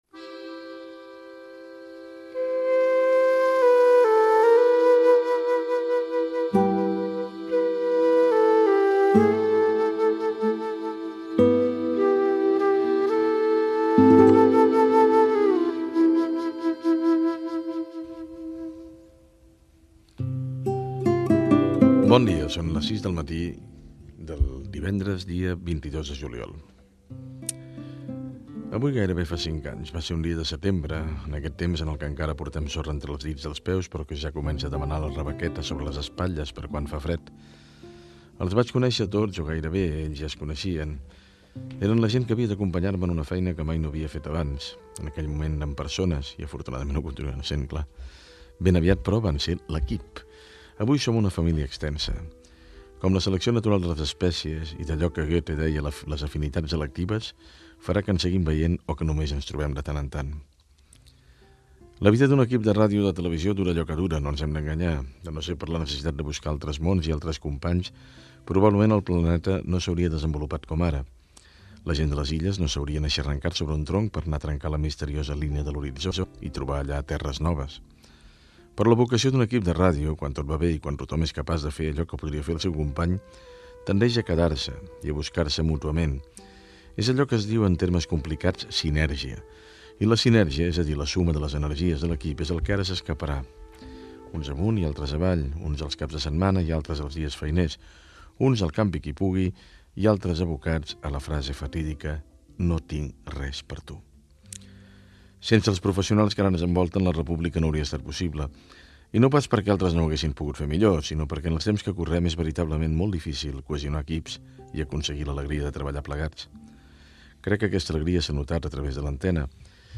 Hora prèvia al programa La R-pública. Hora, data, editorial de Joan Barril sobre l'equip de La R-pública en el seu darrer dia d'emissió. Diàleg dels presentadors, sumari informatiu del programa
Informatiu